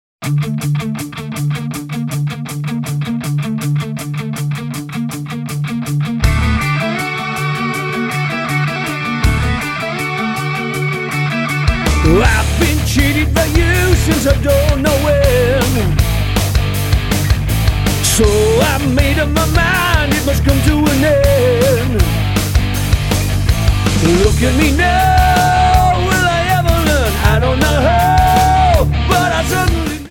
Tonart:D Multifile (kein Sofortdownload.